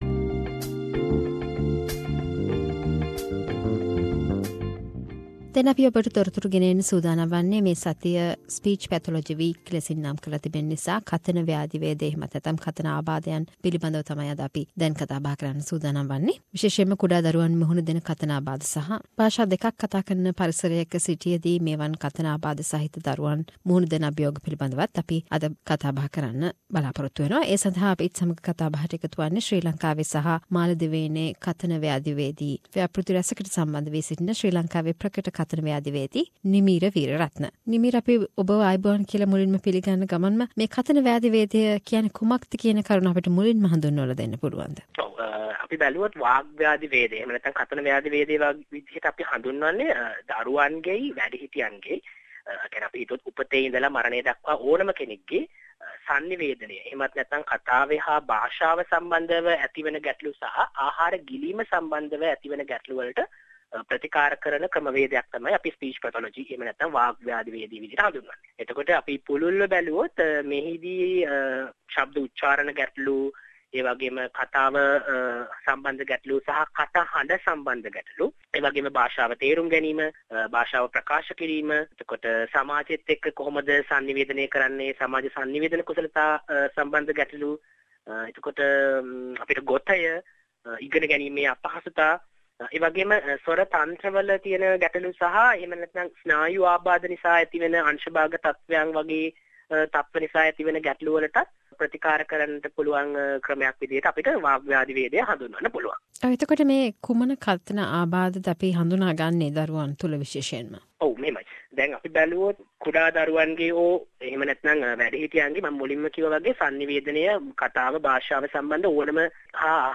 A discussion with speech pathologist